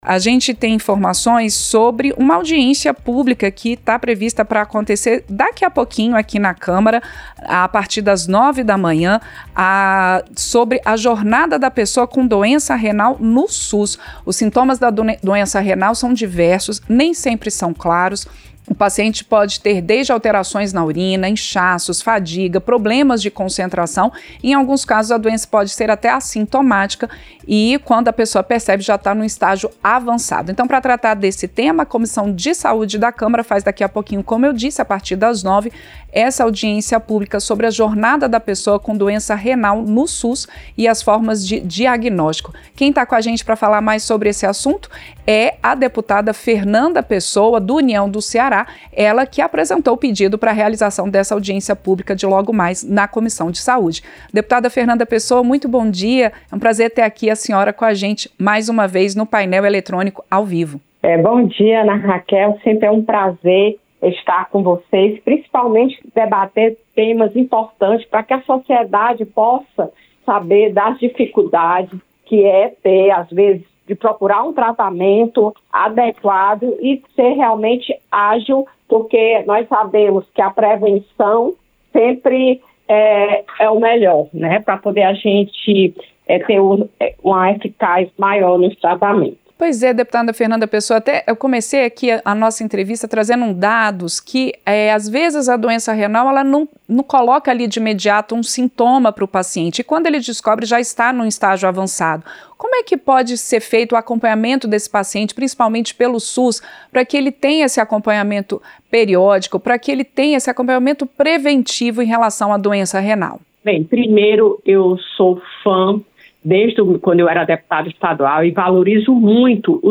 Entrevista - Dep. Dep. Fernanda Pessoa (União-CE)